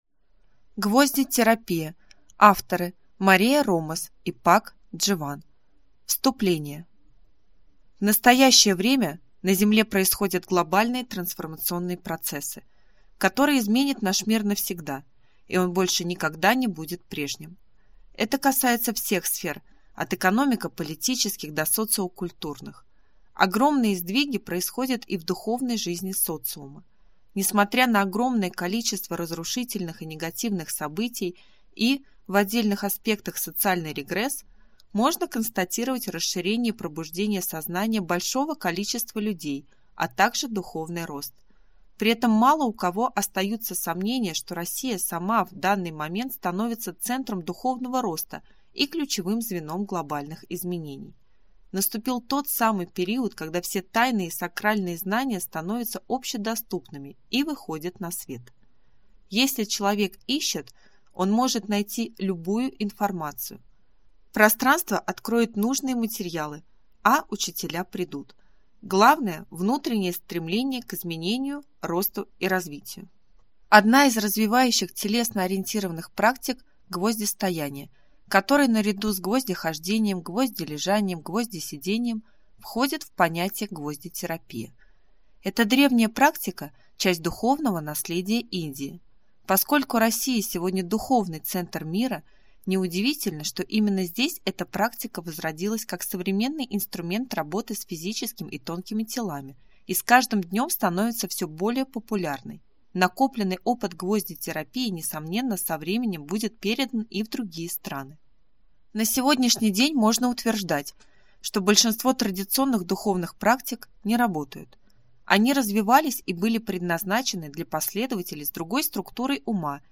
Аудиокнига Гвоздетерапия. Пошаговая инструкция для внутренней трансформации | Библиотека аудиокниг